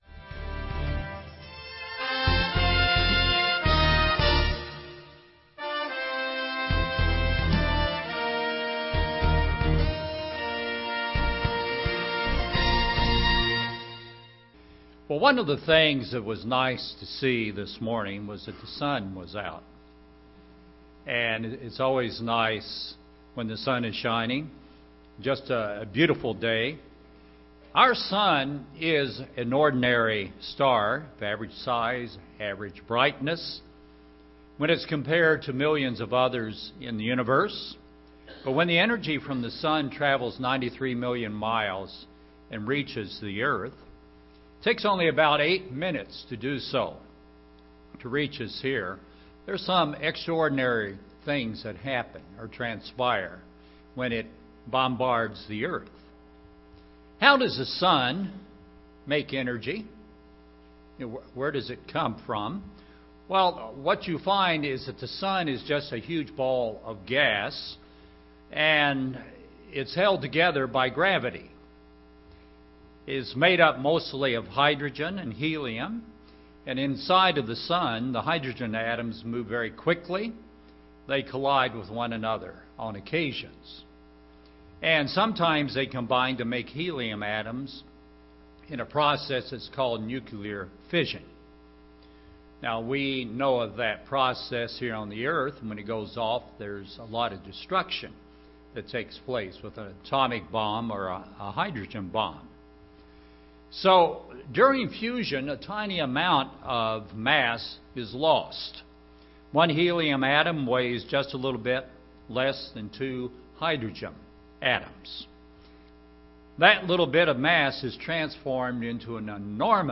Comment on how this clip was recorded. Paul’s prayer for the church was that they would be strengthened with God’s might. As such, we must use the power of God in our lives now and forever. This message was given on the Feast of Pentecost.